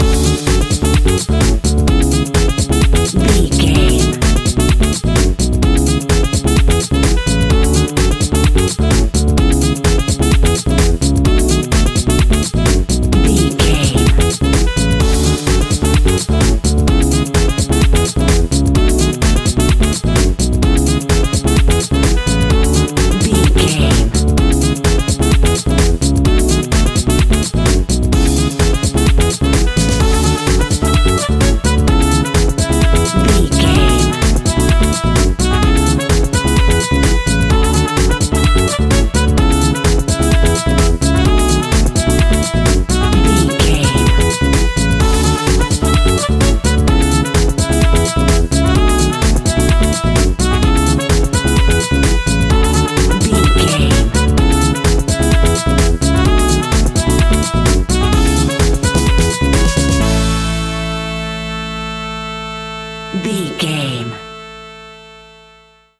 Ionian/Major
groovy
uplifting
bouncy
electric guitar
horns
bass guitar
drums
disco
synth
upbeat
clavinet